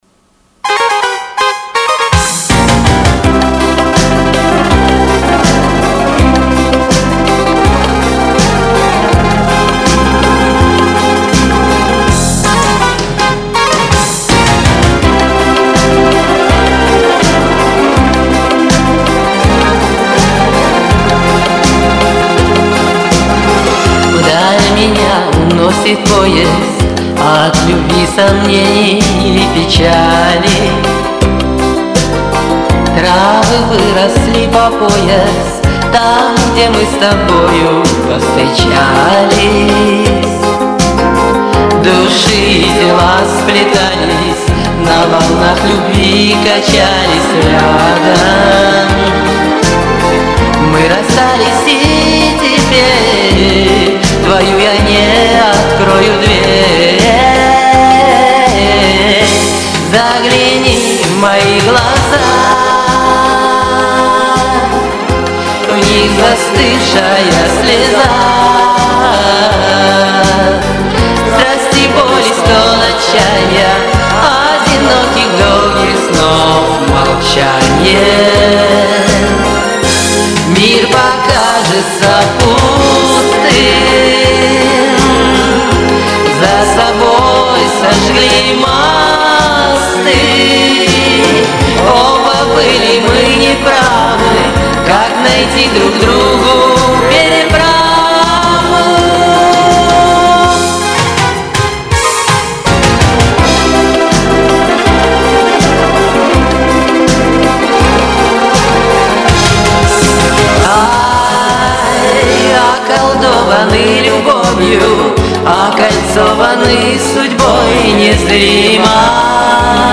звучит по народному лихо, а по вокальному плосковато